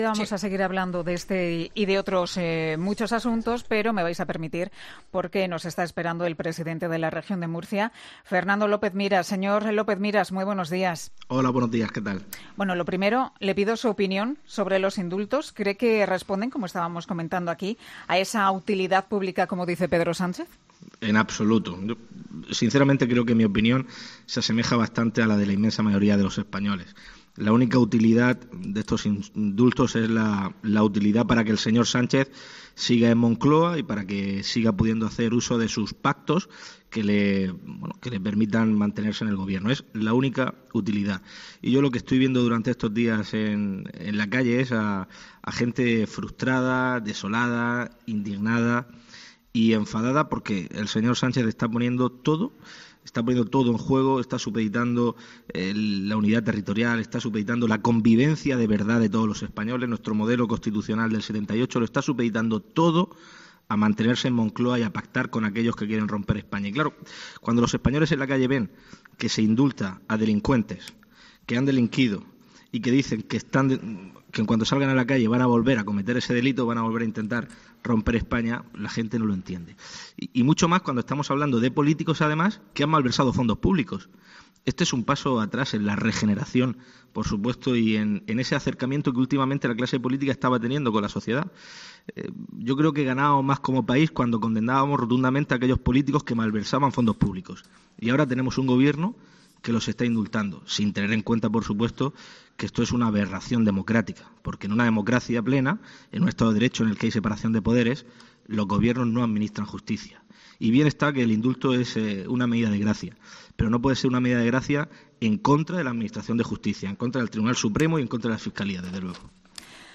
En ‘Herrera en COPE’ hablamos Fernando López Miras, presidente de Murcia, que defiende que “la única utilidad de estos indultos es la utilidad para que el señor Sánchez siga en Moncloa y para que siga pudiendo hacer uso de sus pactos que le permitan mantenerse en el Gobierno, es la única utilidad”.